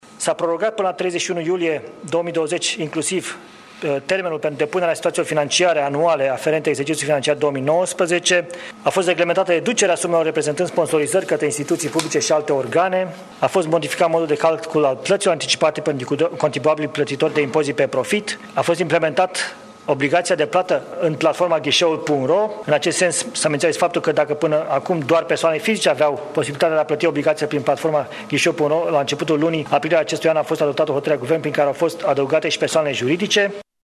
În ședința de guvern au mai fost luate și alte decizii. Ministrul Florin Cîțu: